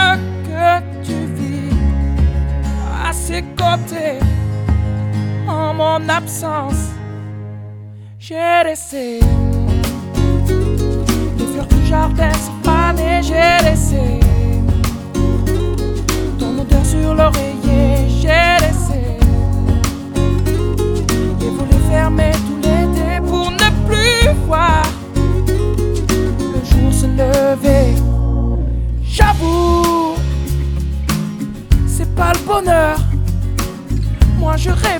Жанр: Поп музыка / Рок / Соундтрэки / Альтернатива